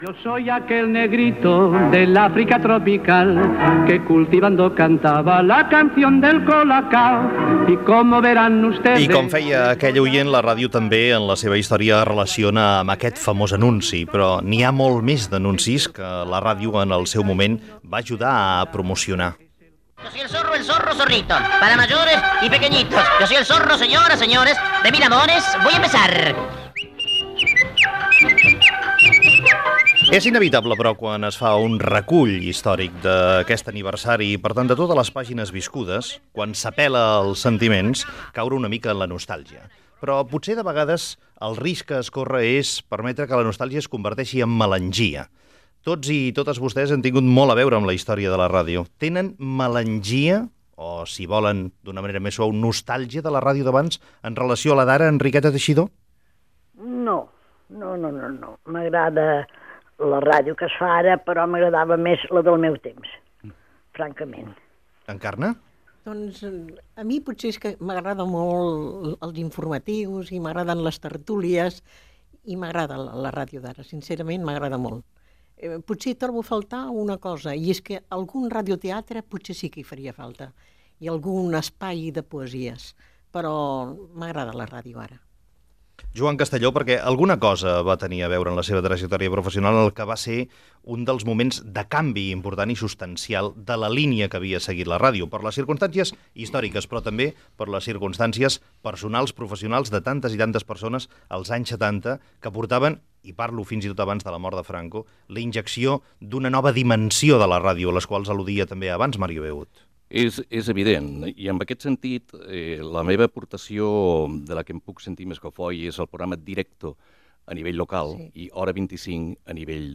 Espai dedicat al 75è aniversari de Ràdio Barcelona. Taula rodona
Info-entreteniment
Fragment extret de l'arxiu sonor de COM Ràdio.